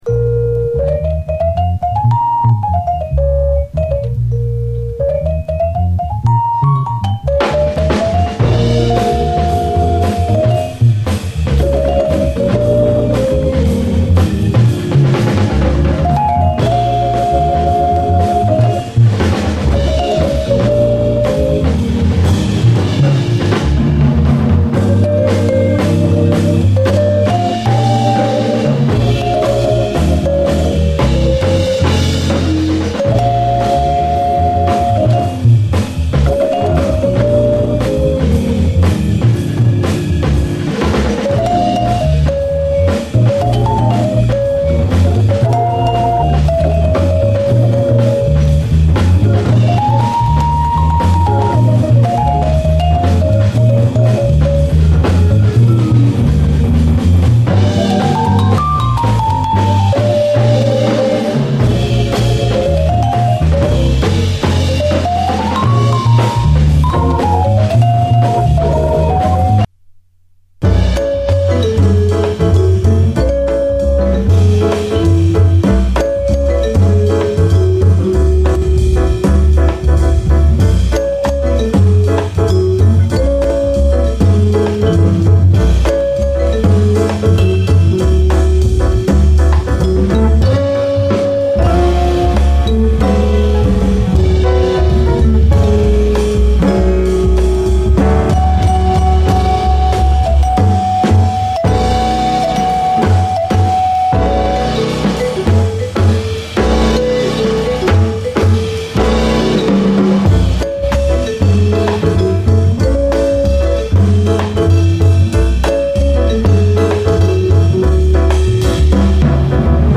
JAZZ FUNK / SOUL JAZZ, JAZZ
ヒップホップ・ネタ多数、ヴィブラフォンが美しく揺れるメロウ・ジャズ・ファンク！